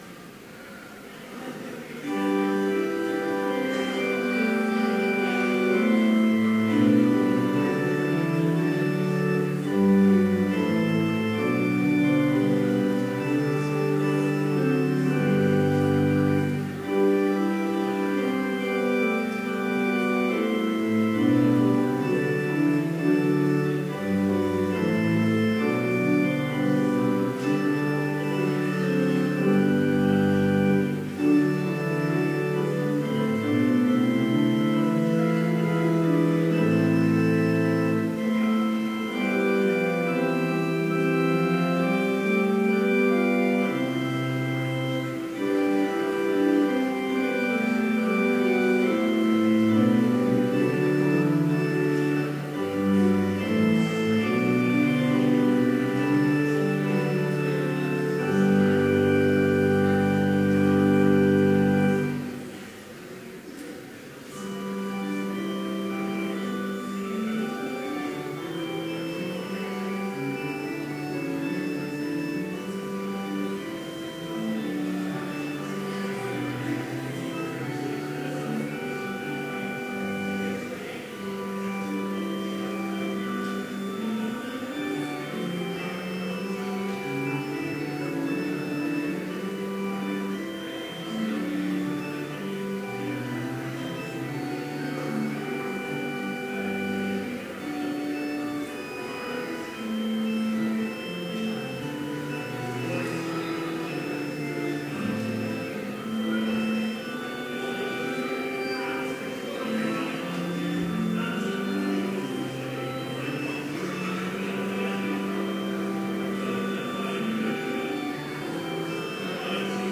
Complete service audio for Chapel - September 5, 2017
Order of Service Prelude Hymn 29, vv. 1, 3 & 5, Open Now Thy Gates Reading: Psalm 1 (ESV) Devotion Prayer Hymn 457, Blessed is the Man Blessing Postlude